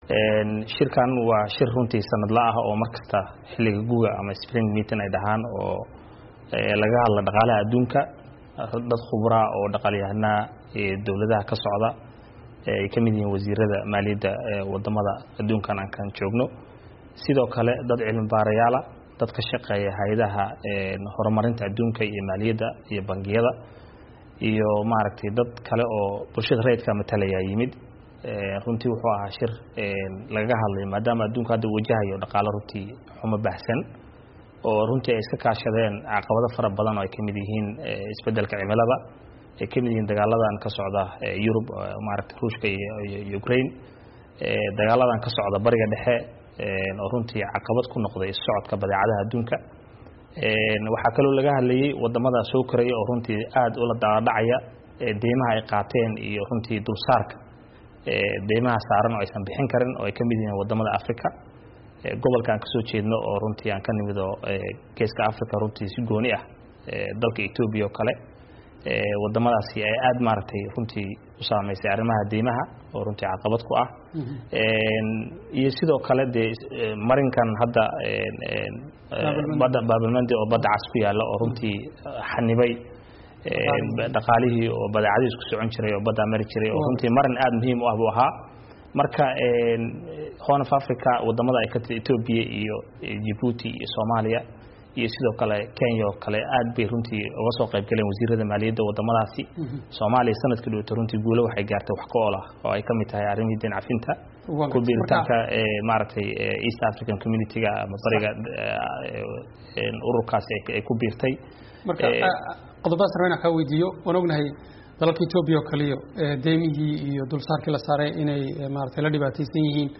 Wareysi: Maxay Soomaaliya la tagtay shirkii IMF iyo Bankiga Adduunka?